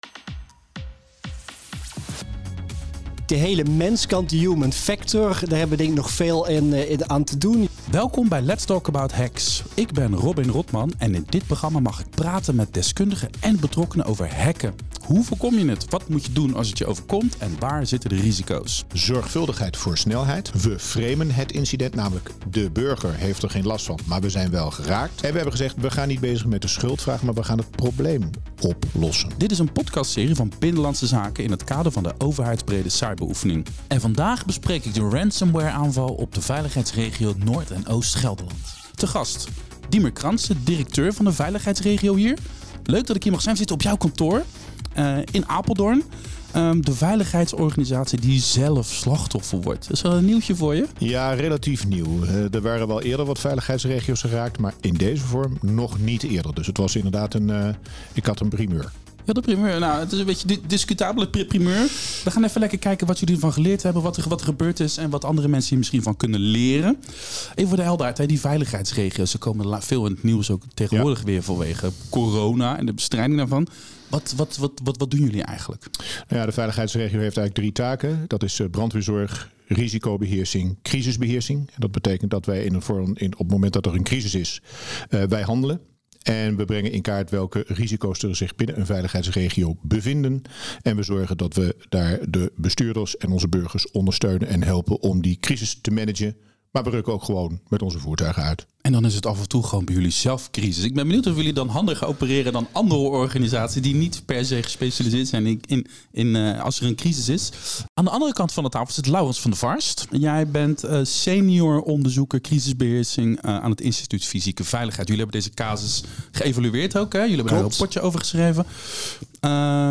We zitten op jouw kantoor in Apeldoorn.